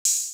OpenHat [Gang].wav